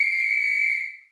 sifflet-3731dca2.mp3